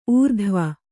♪ ūrdhva